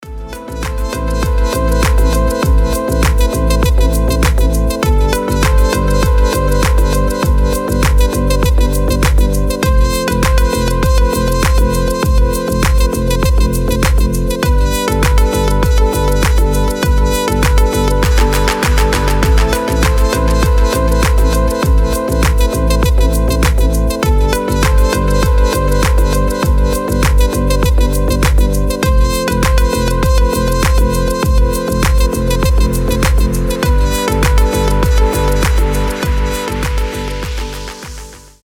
• Качество: 320, Stereo
deep house
мелодичные
без слов
релакс
расслабляющие